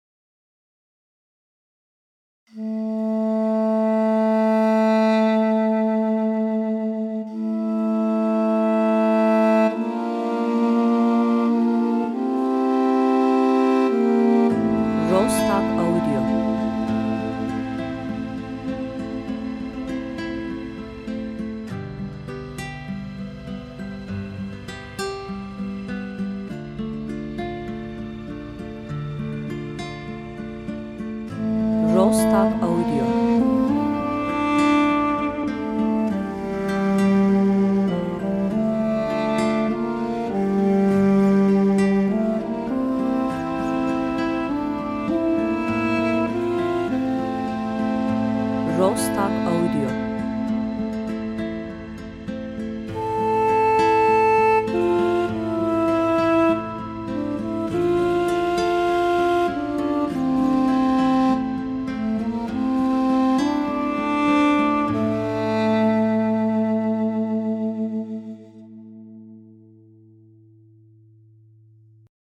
enstrümantal
duygusal